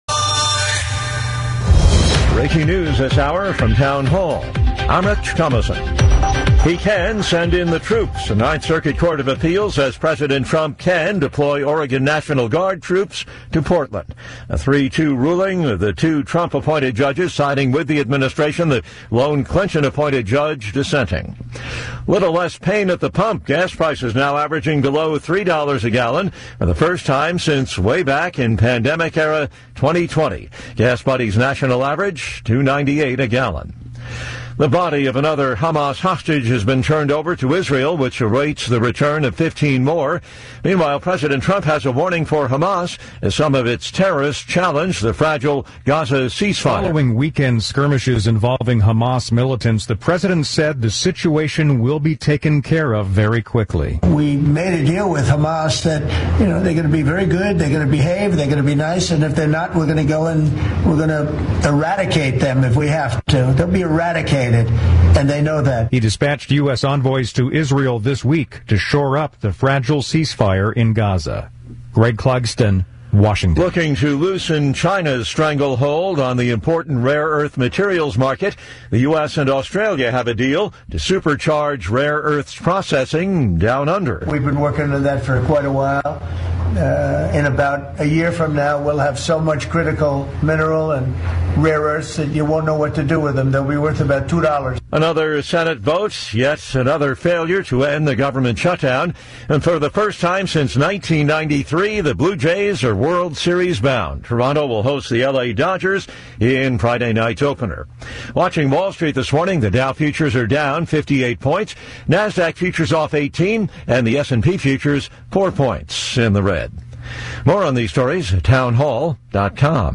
Guest Wicomico Co Sheriff Mike Lewis lights up the left regarding his intention to work with ICE to protect Marylanders, Ivan Bates to investigate Safe Streets? What was causing bad traffic in Towson on a Saturday and more.